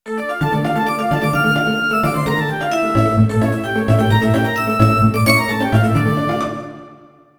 Tonalidad de Re menor. Ejemplo.
tristeza
dramatismo
melodía
serio
severo
sintetizador